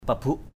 /pa-buʔ/ I. pabuk pb~K 1.
pabuk.mp3